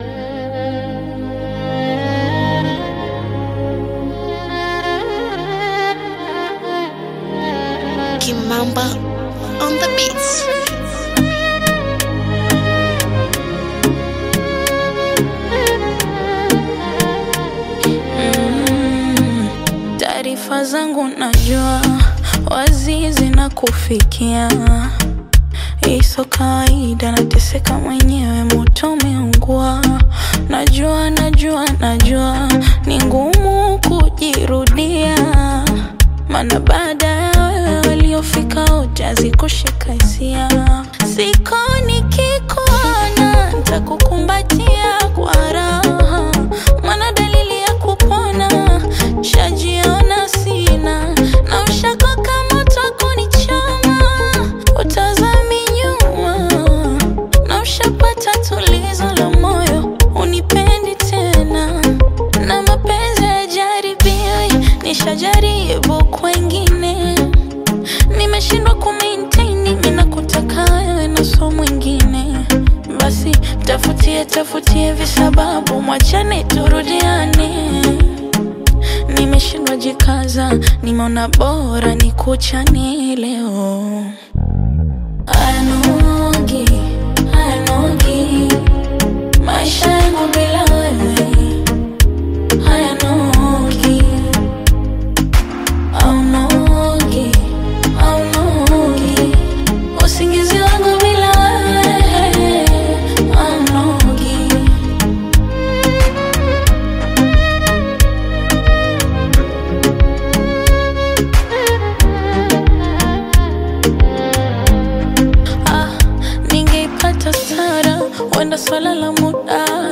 blends soft melodies with modern production